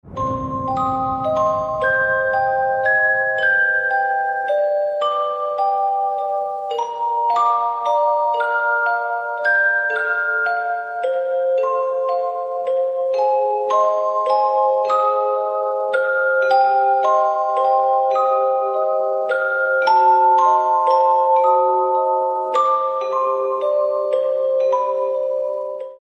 • Качество: 256, Stereo
без слов
инструментальные